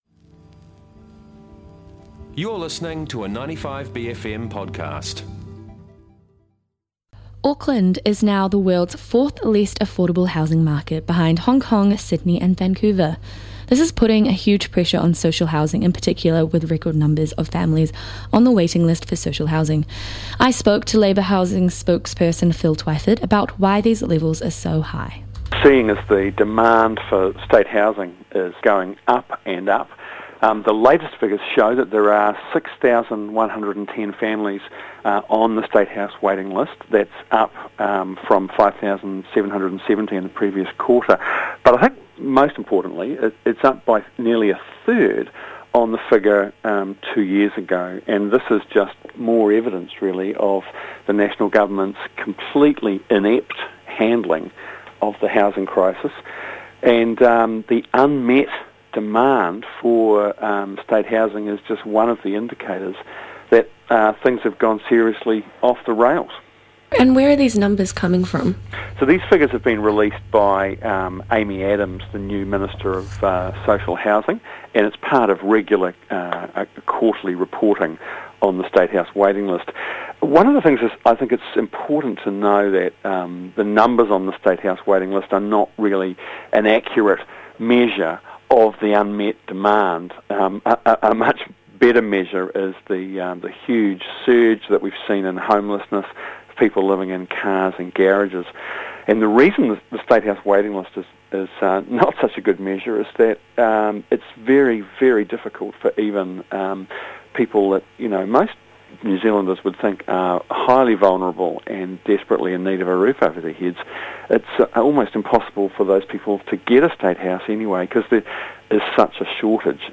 Auckland is now the world's fourth least affordable housing market behind Hong Kong, Sydney and Vancouver. This is putting huge pressure on social housing in particular with record numbers of families on the waiting list for social housing. 95bFM producer spoke to Labour Housing spokesperson Phil Twyford about why these levels are so high.